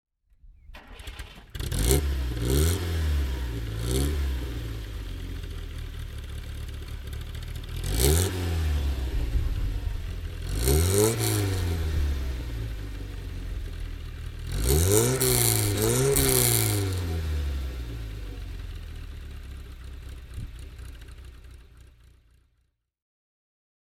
Datsun 1600 Sports (1968) - Starten und Leerlauf